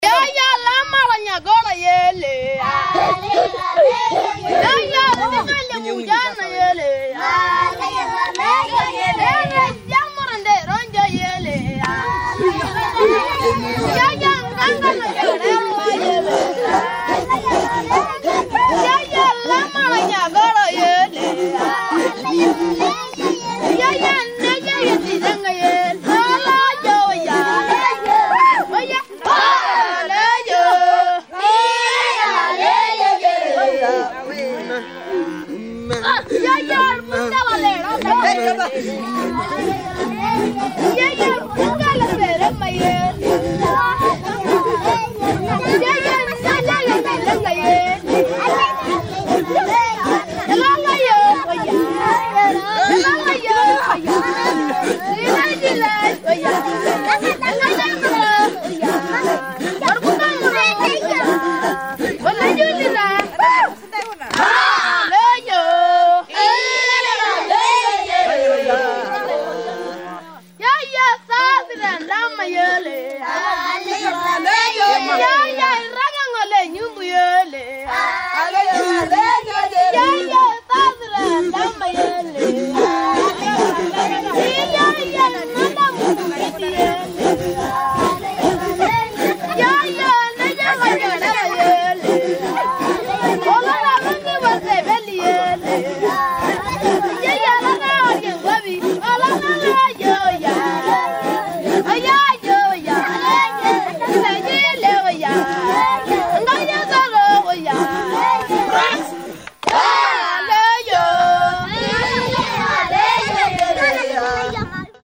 Just as we were getting ready to call it quits for the day, a group what looked like teenage boys and pre-pubescent girls arrived and started up a courtship dance.
The boys sang and bobbed their upper torsos to the rhythm of their song.
They would scamper back into line because the boys would all jump forward making menacing animal noises.
The evening dance with the boys lined up on one side and the girls on the other.